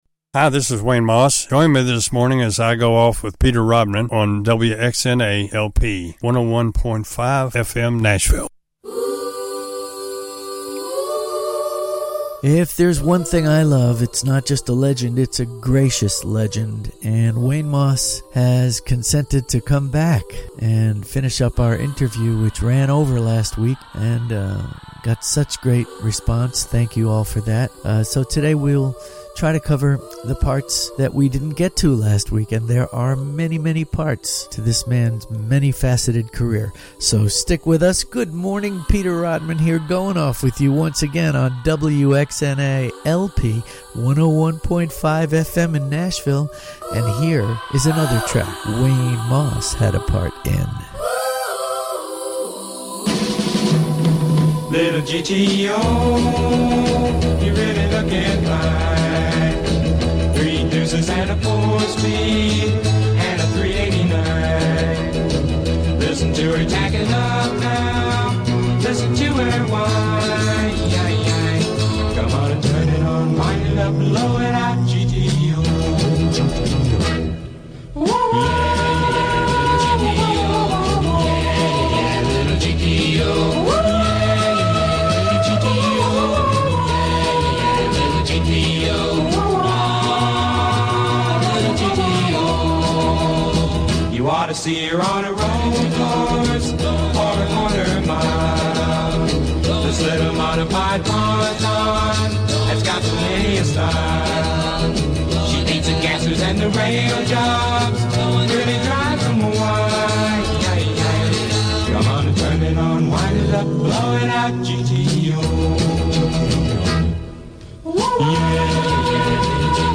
Wayne Moss Interview on WXNA Radio Nashville